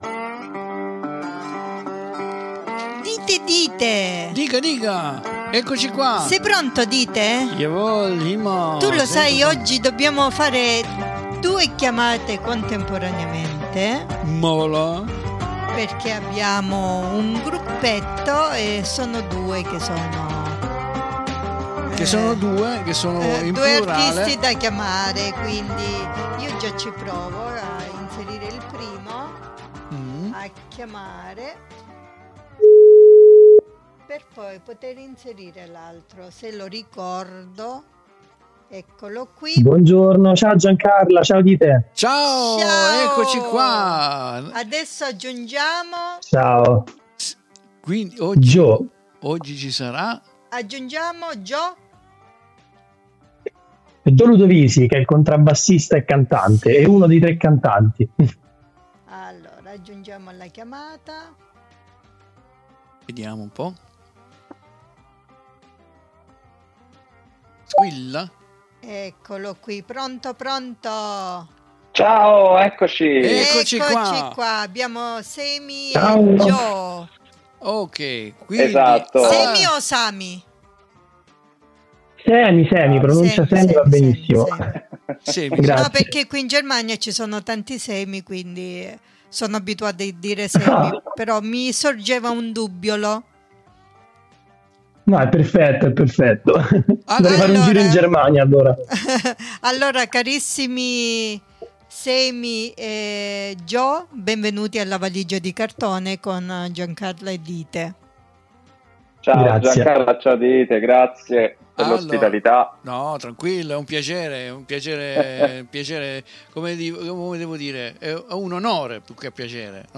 94816_INTERVISTA_Darnaut.mp3